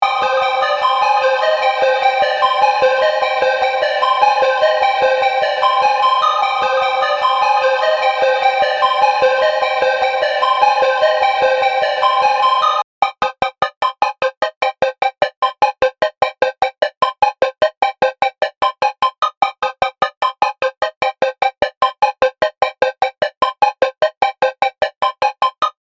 Synth | Preset: Snappy Synth Pluck (DRY→WET)
Articulate-Synth-Snappy-Synth-Pluck-1.mp3